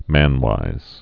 (mănwīz)